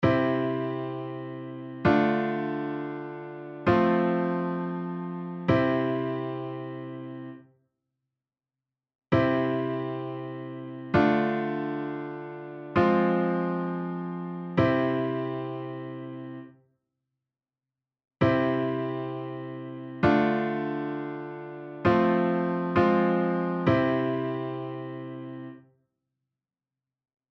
• 元となるC⇒Dm⇒F⇒C（I⇒IIm⇒IV⇒C）
• FをサブドミナントマイナーのFmに変更して、C⇒Dm⇒Fm⇒C
• Fを「前半Fのまま、後半Fm」にして、C⇒Dm⇒|F Fm|⇒C
の3つを続けて弾いたものです。
サブドミナントマイナーがあると、なんとも切ない感じがしますね。